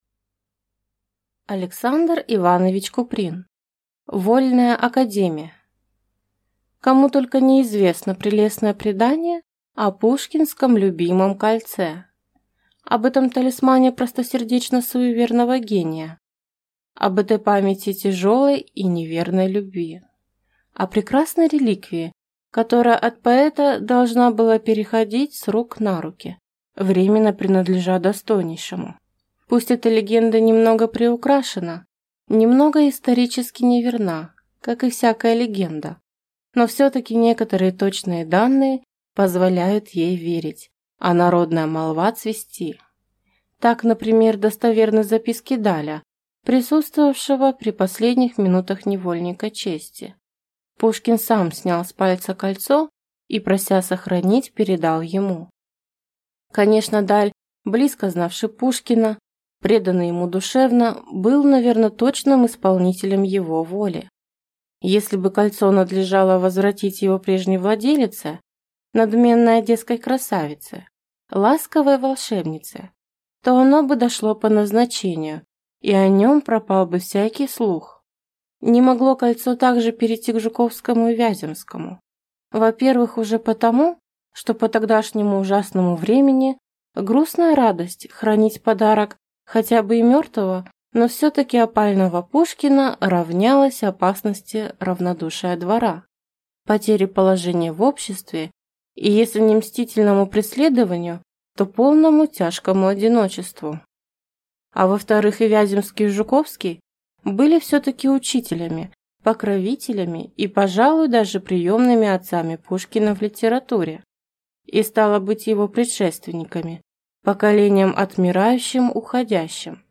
Аудиокнига Вольная академия | Библиотека аудиокниг